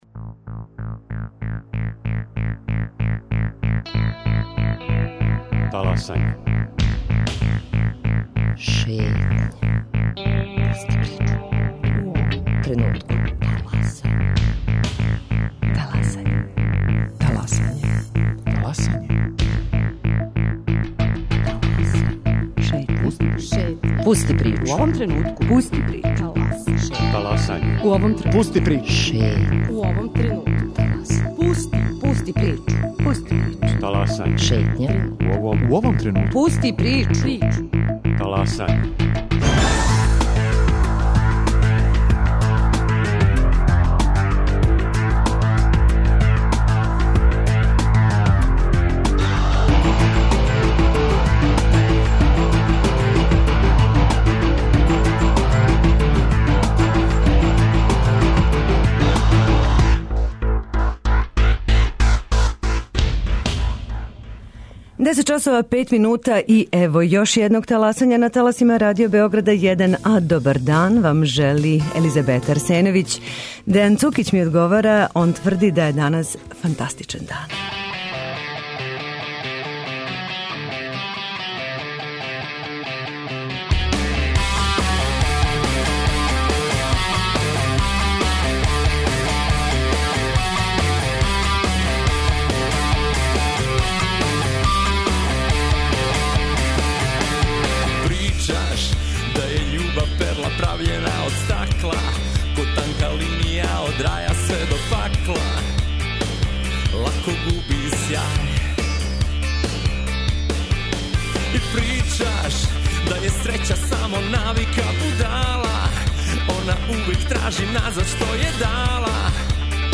Две гошће у данашњој емисији, из исте институције - Централног института за конзервацију (ЦИК) у Београду, али различитим поводима.